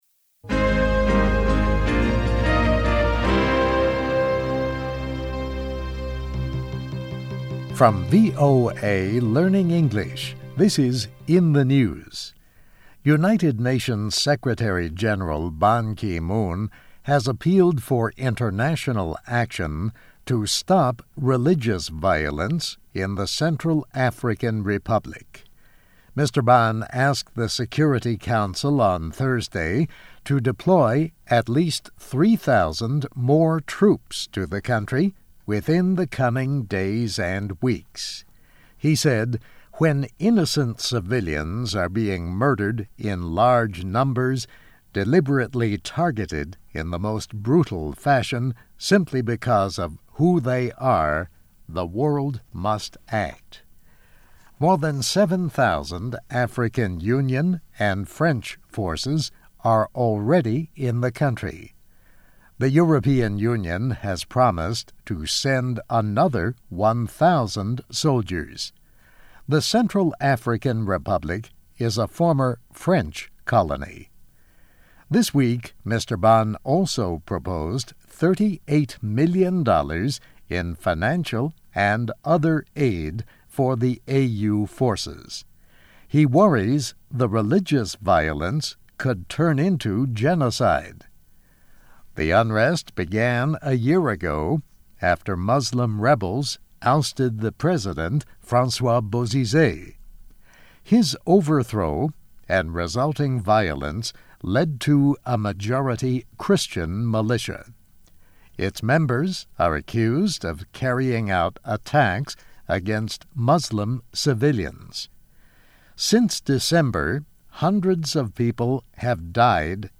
VOA慢速英语, In the News, 联合国秘书长希望向中非共和国再派遣3000名士兵